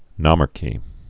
(nŏmärkē)